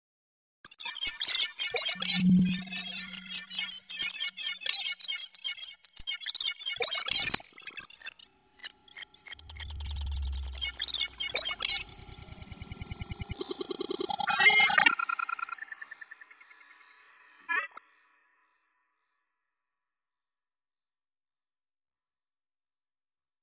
دانلود صدای ربات 48 از ساعد نیوز با لینک مستقیم و کیفیت بالا
جلوه های صوتی